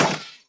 Shadoks Swoosh and Hit 3